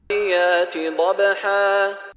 Contoh Bacaan dari Sheikh Mishary Rashid Al-Afasy
DIPANJANGKAN KETIKA BERHENTI dibaca dengan baris satu di atas berserta 2 harakat.